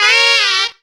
BENDER SAXES.wav